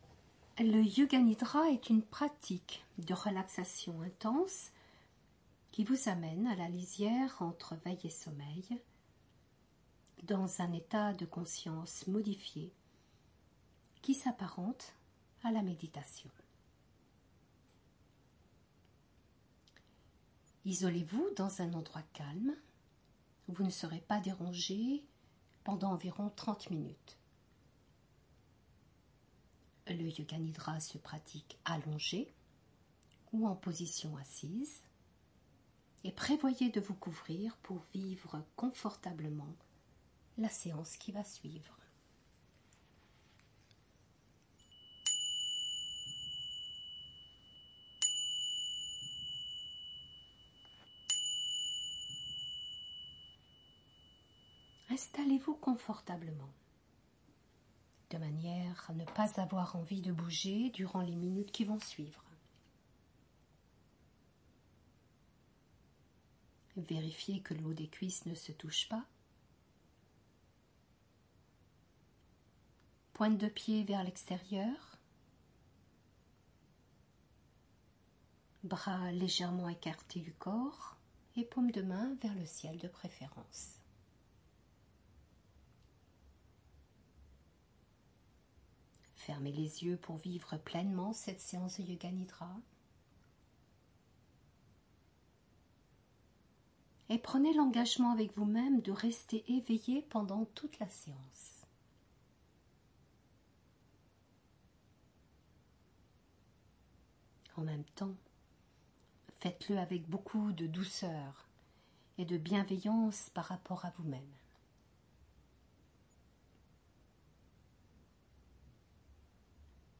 Entre veille et sommeil, entre conscience et rêve, le yoga nidra permet d’approcher des états de conscience modifiés, et d’entrer dans un état proche de la méditation grâce au guidage du professeur.